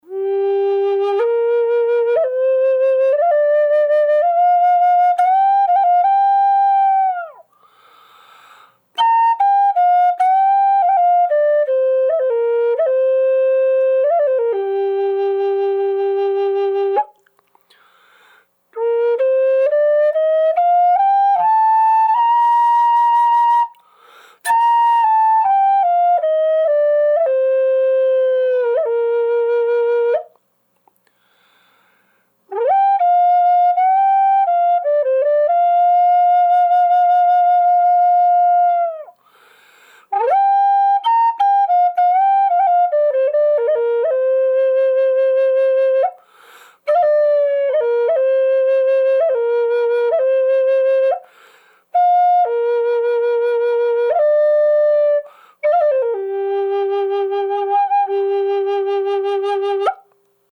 The sound is as big as any of my Gm flutes.